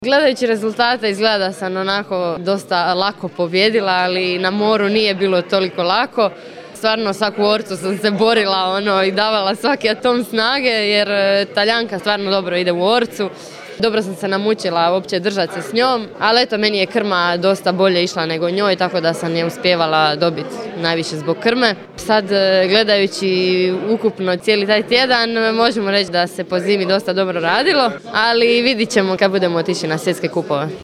MP3 izjava